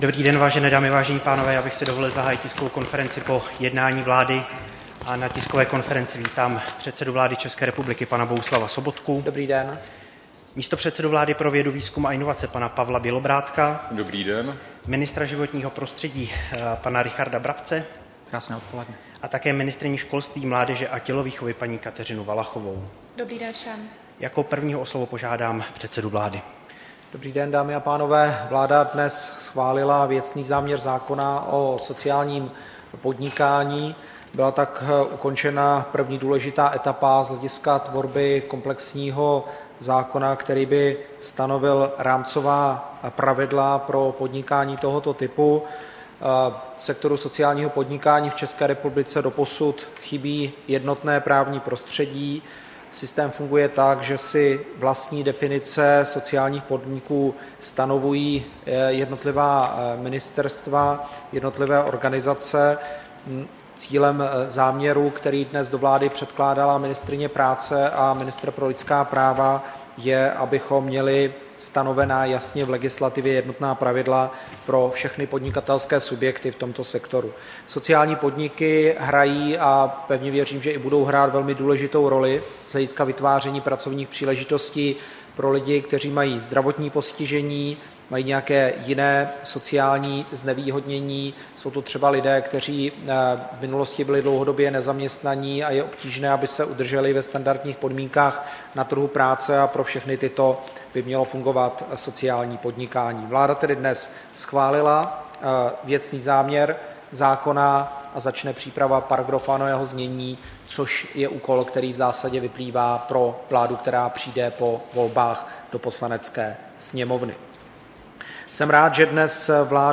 Tisková konference po jednání vlády, 15. května 2017